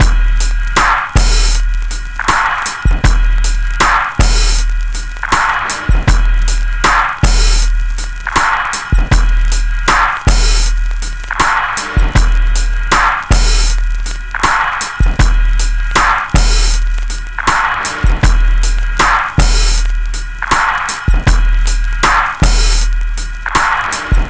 Breakbeat 2
Squeaky Rim 079bpm